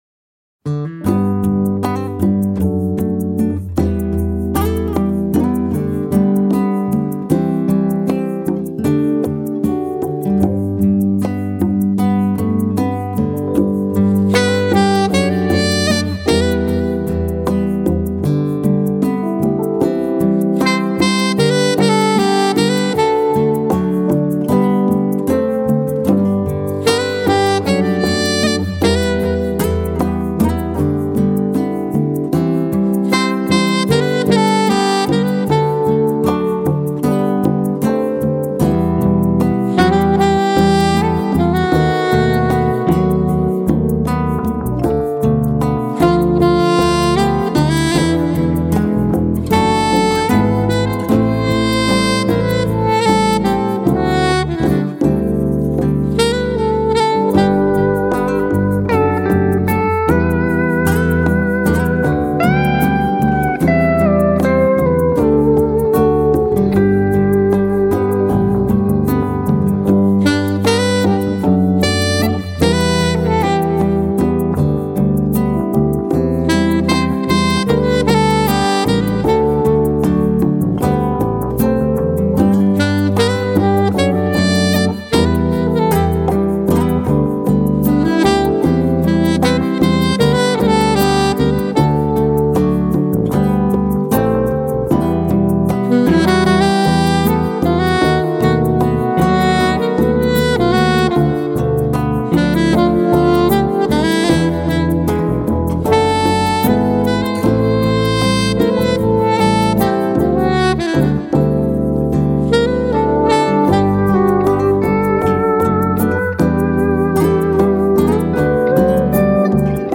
ژانر : Smooth Jazz